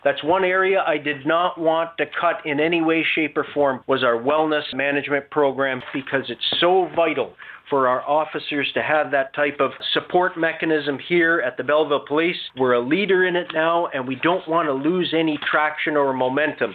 Due to ongoing social and physical distancing restrictions, Thursday morning’s regular meeting was held via a teleconference call.